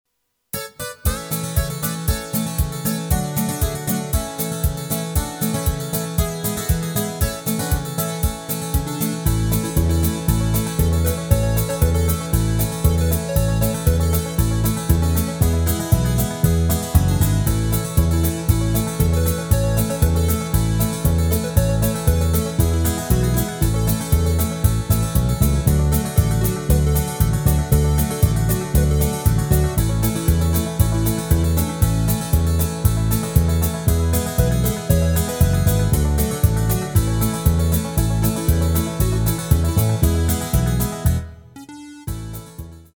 Rubrika: Pop, rock, beat
Karaoke
Předehra: banjo - G, C, G, D, G